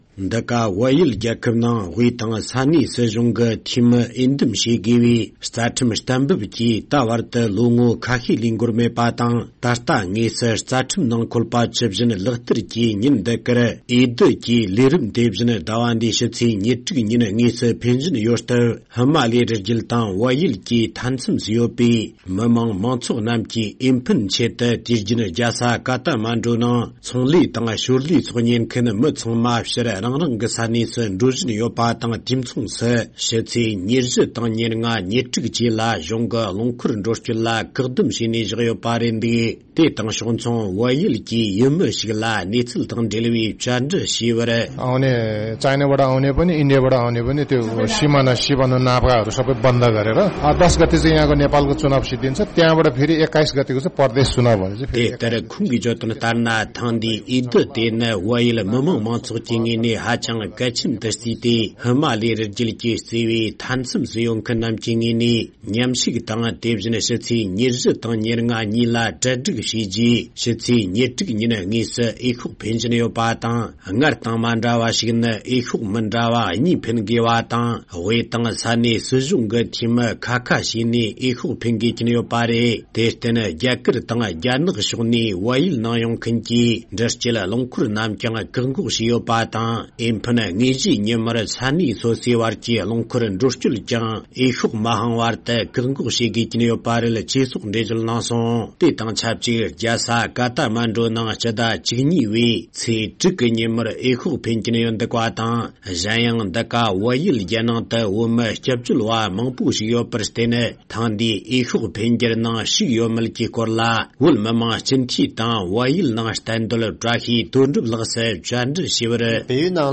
བལ་ཡུལ་ནས་བཏང་བའི་གནས་ཚུལ་ཞིག